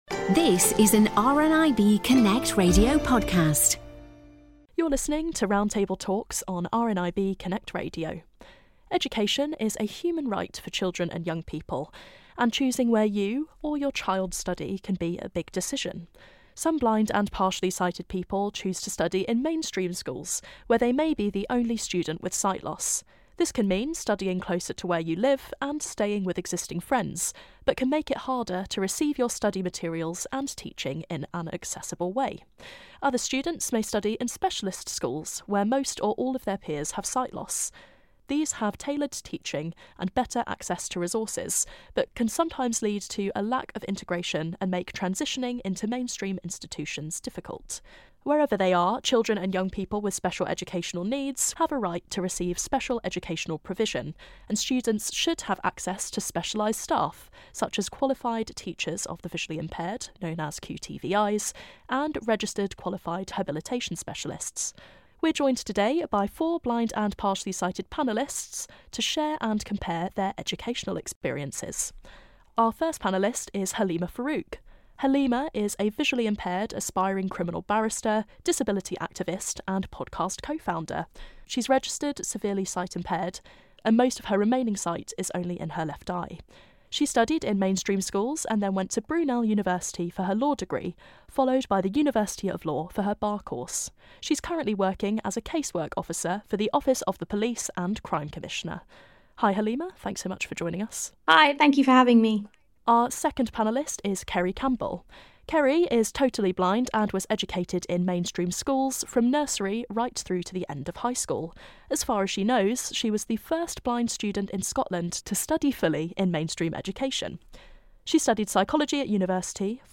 Education - Roundtable
We've brought together four current and graduated students to talk all things Education in our latest Roundtable.